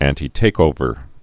(ăntē-tākōvər, ăntī-)